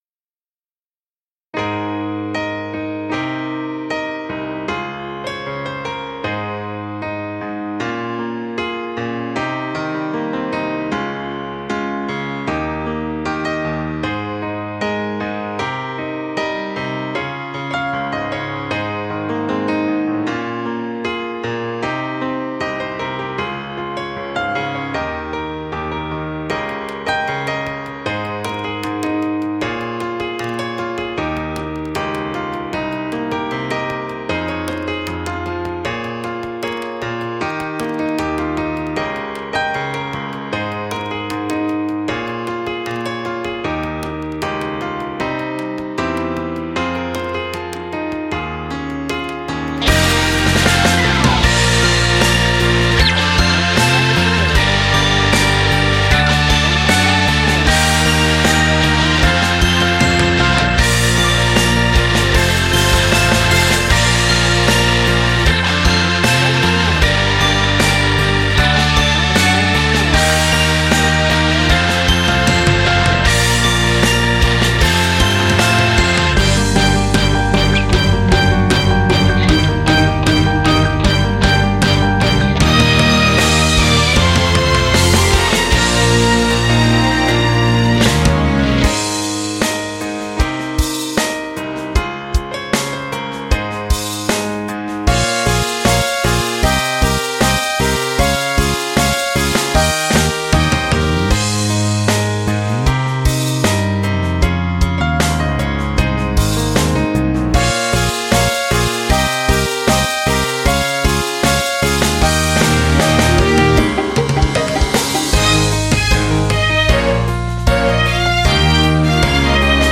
Vocaloid (ニコニコ動画マイリストへ)